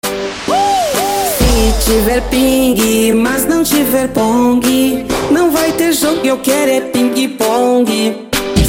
Sound Buttons: Sound Buttons View : Ping Pong
ping-pong.mp3